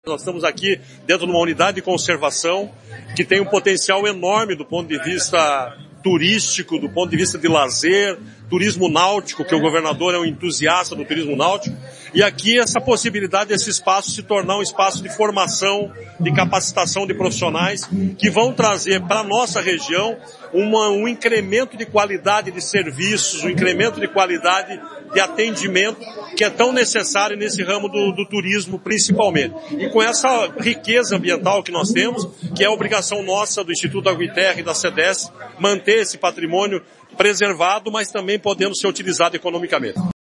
Sonora do diretor-presidente do IAT, Everton Souza, sobre a Escola do Mar no Litoral